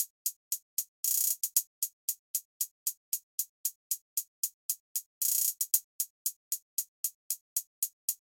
令人毛骨悚然的成见氛围
描述：用免费的Stigma仿真器制作的万圣节风格的氛围
Tag: 160 bpm Electronic Loops Synth Loops 2.02 MB wav Key : Cm Cubase